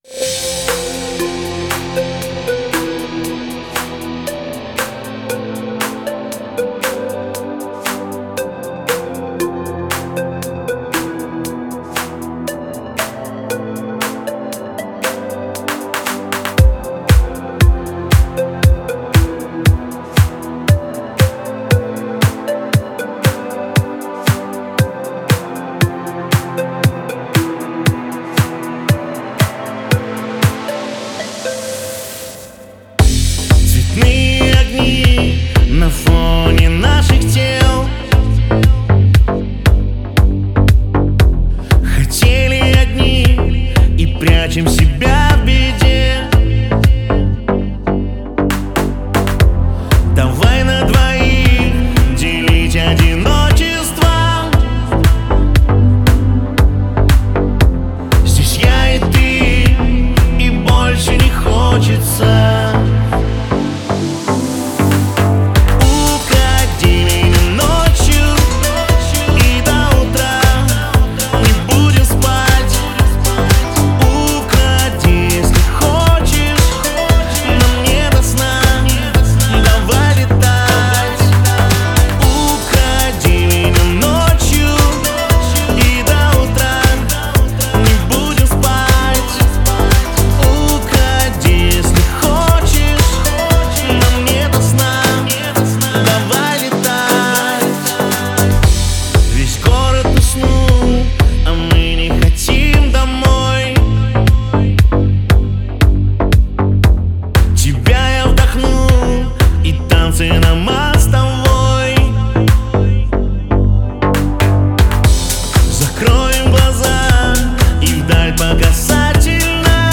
Стиль: Pop / Dance